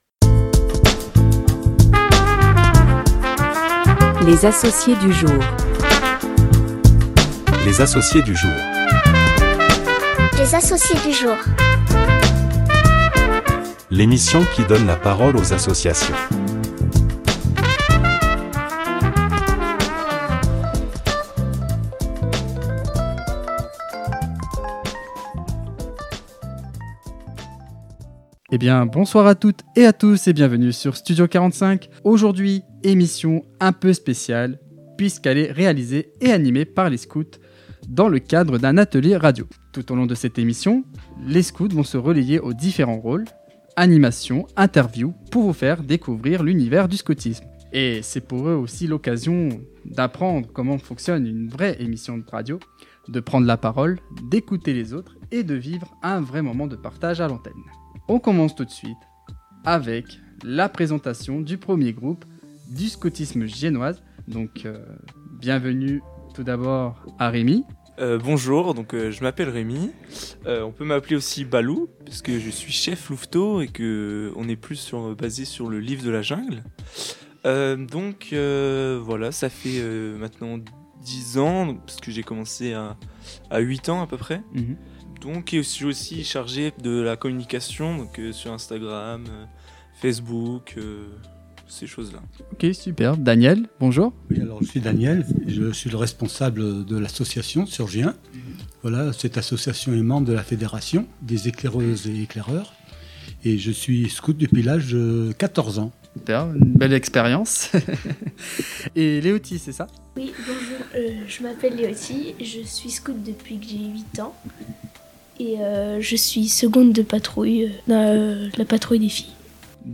Dans cet épisode inédit des Associés du Jour, les jeunes de l’association Scoutisme de Gien prennent les rênes de l’émission et deviennent, le temps d’un podcast, animateurs, journalistes et intervieweurs.